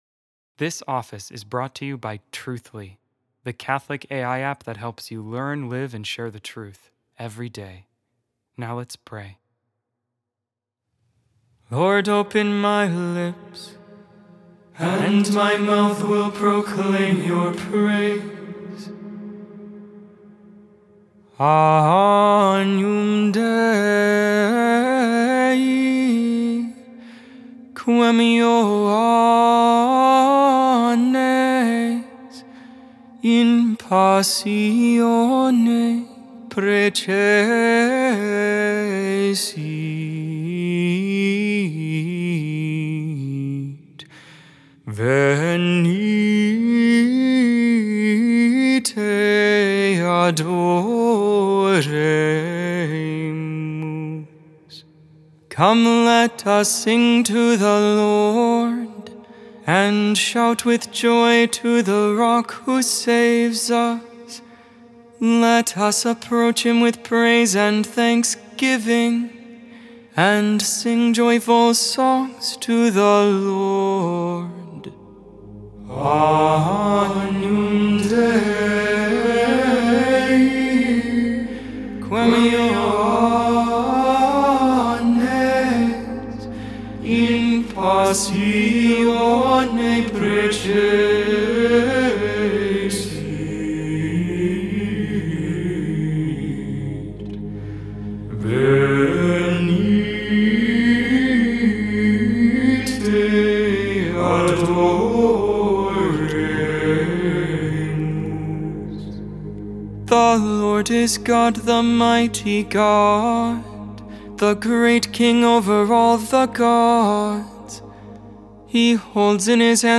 8.29.25 Lauds, Friday Morning Prayer of the Liturgy of the Hours